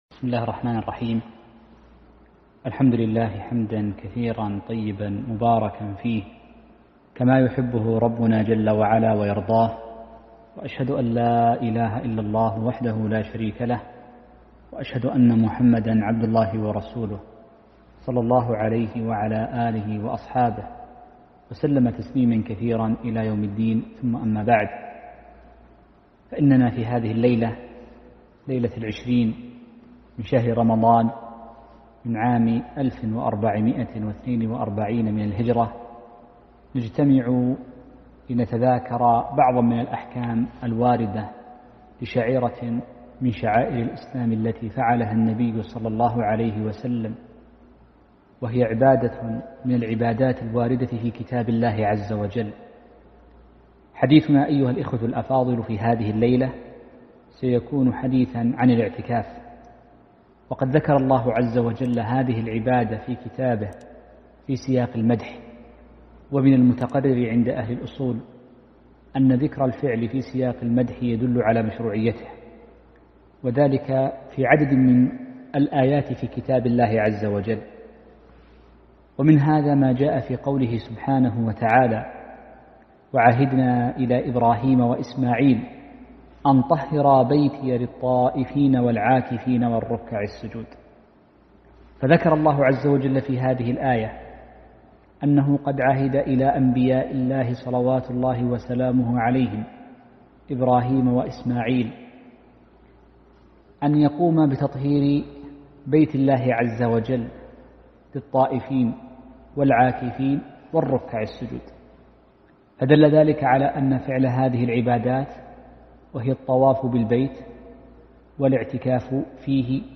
محاضرة - أحكام الاعتكاف ونوازله